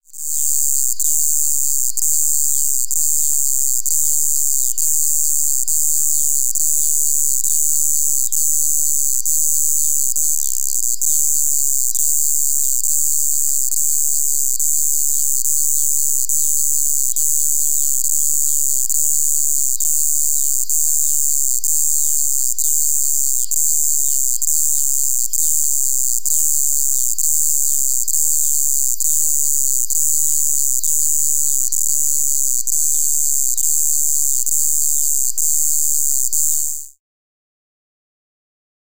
Cicadas emit species-specific tymbalizations (songs), which are available online as supporting material to this volume (111 downloadable audio tracks).
Cicada tymbalization downloads